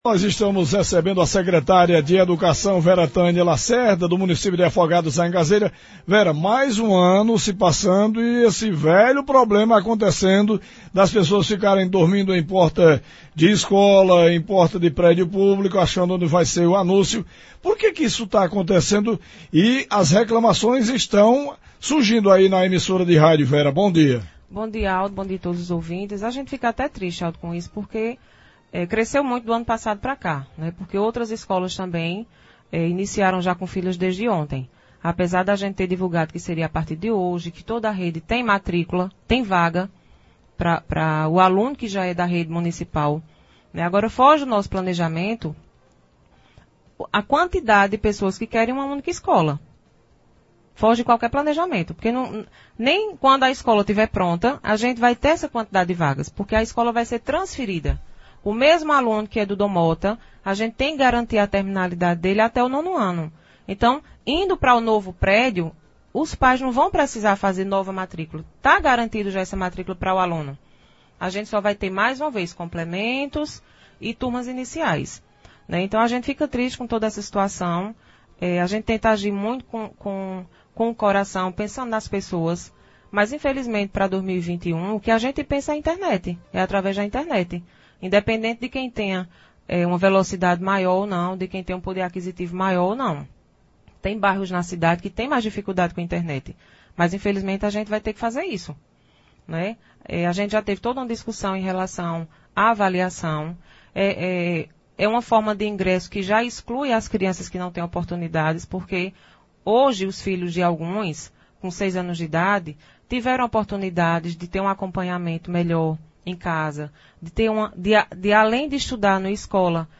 A secretária de Educação de Afogados da Ingazeira, Veratânia Morais, falou ao programa Manhã Total da Rádio Pajeú FM desta segunda-feira (06.01), que lamenta a correria e a confusão durante matrículas para as escolas Padre Carlos Cottart e Dom Mota que aconteceu na manhã de hoje no Salão Paroquial.